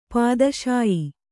♪ pādaśayi